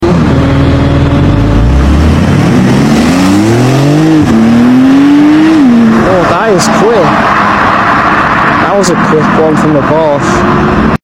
Porsche 911 Turbo S Rapid Sound Effects Free Download